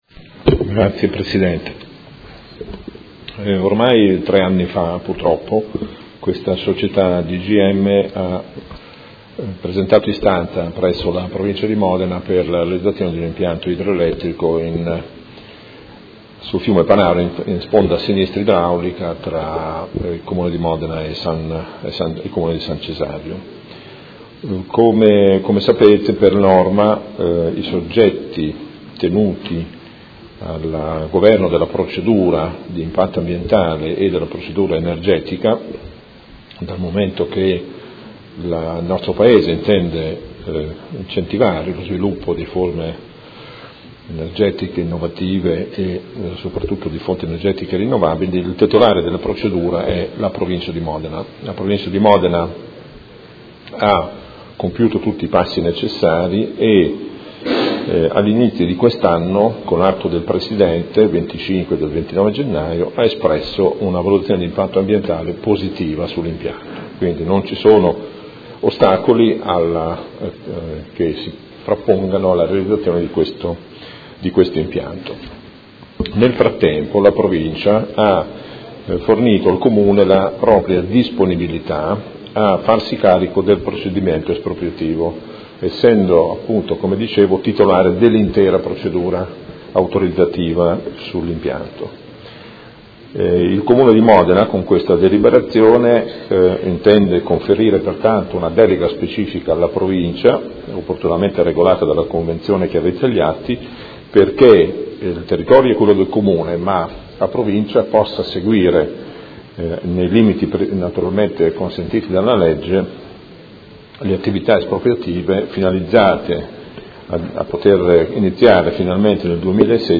Gabriele Giacobazzi — Sito Audio Consiglio Comunale
Seduta del 10/12/2015. Proposta di deliberazione: Progetto di impianto idroelettrico sul Fiume Panaro, Località San Donnino, Comune di Modena, proponente Società DGM SRL – Approvazione schema convenzione con la Provincia di Modena per la gestione del procedimento espropriativo